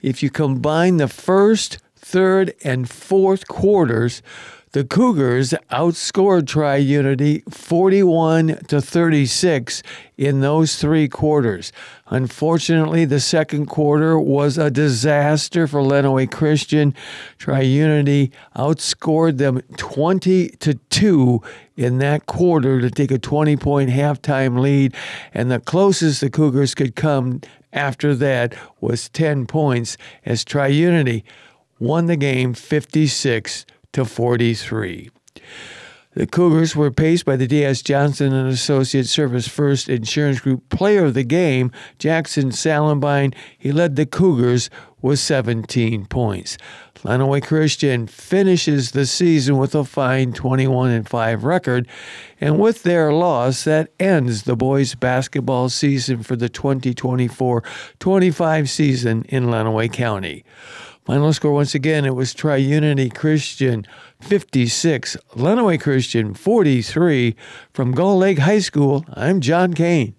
nws8521-aaa_sports_wrap.wav